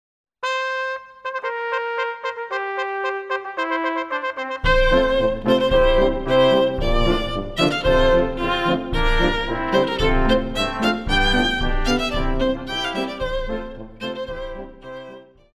Pop
Violin
Band
Instrumental
World Music
Only backing